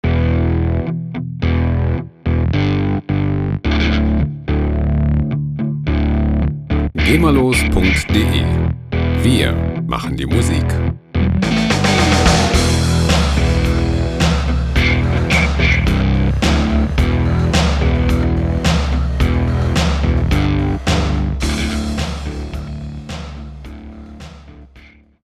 Heavy Metal Loops
Musikstil: Hard Rock
Tempo: 108 bpm